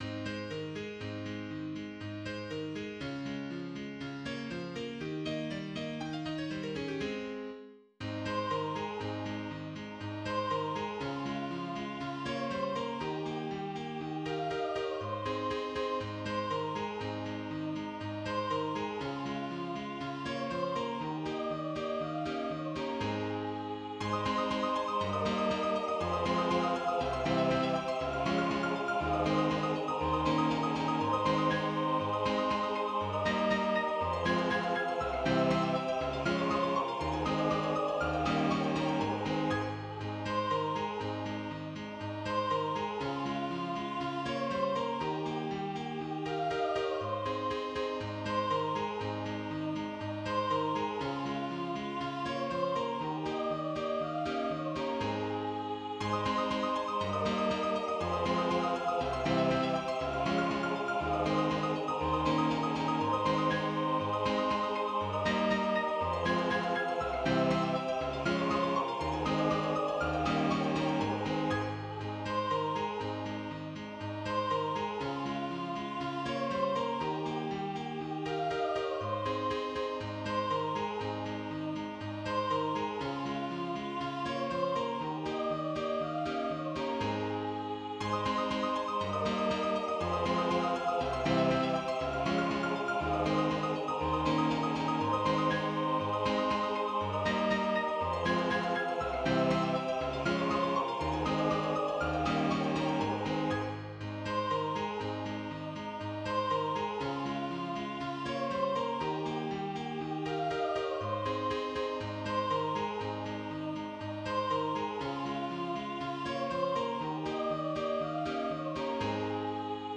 full song Structure: Intro, Verse I, Chorus, Verse II, Chorus, Verse III, Chorus, Verse IV, Chorus, Outro.
Jingle_Bells_full_Ab.mid.mp3